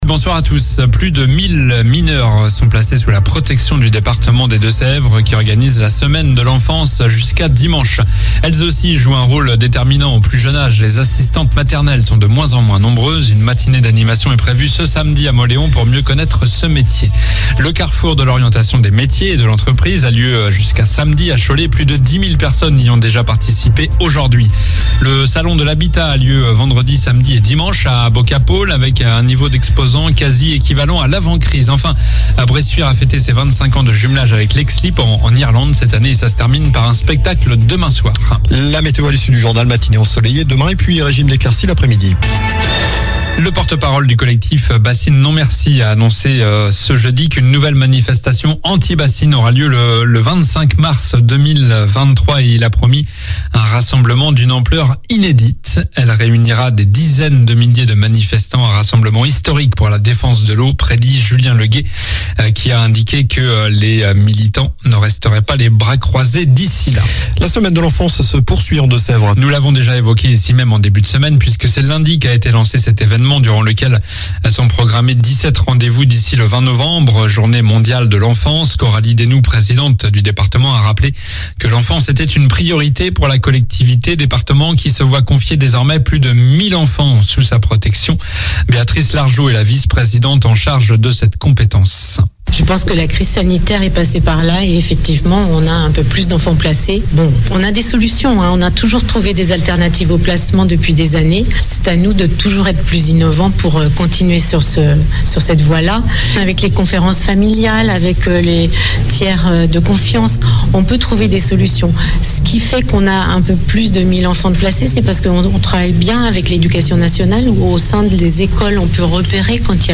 Journal du jeudi 17 novembre (soir)